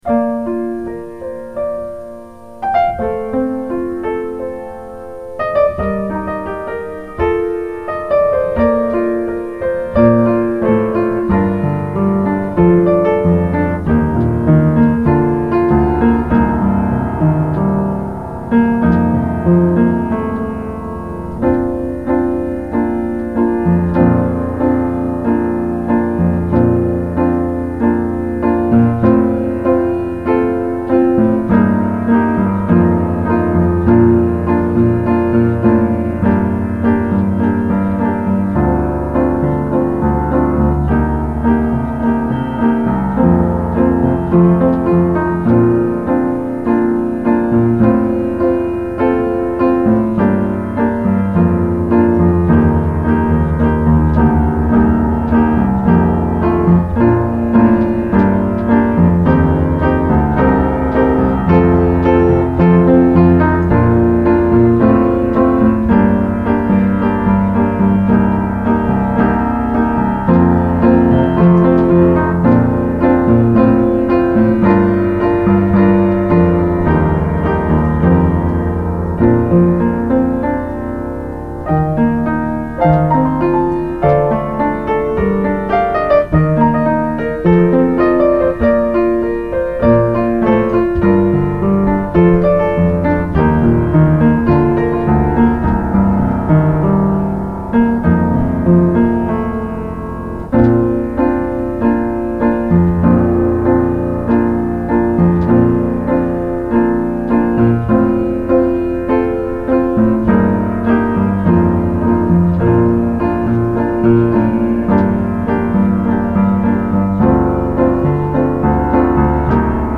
ピアノが弾けてた頃
・全体的にへたくそ・楽譜通り弾けてない・頻回にトチる・ペダルの使い方がよくわかってない
上記の通りひっどいですが回顧と供養のためさらします←追記から、音量注意です
旅立ちの日に伴奏練習.mp3